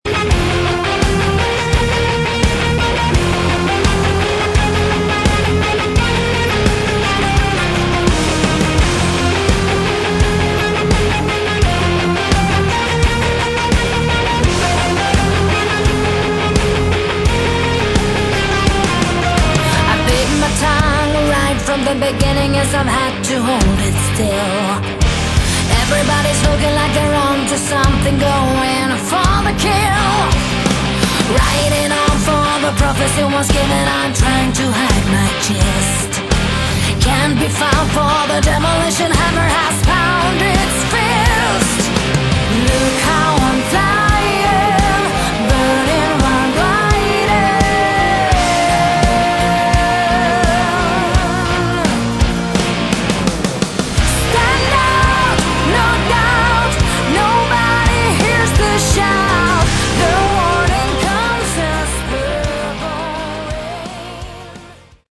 Category: Melodic Metal
Lead & Backing Vocals
Guitars
Drums
Bass
Keyboards & Programming